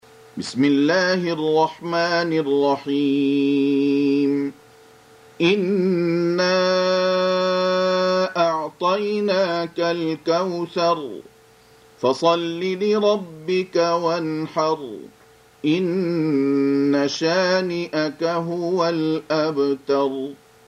Surah Repeating تكرار السورة Download Surah حمّل السورة Reciting Murattalah Audio for 108. Surah Al-Kauthar سورة الكوثر N.B *Surah Includes Al-Basmalah Reciters Sequents تتابع التلاوات Reciters Repeats تكرار التلاوات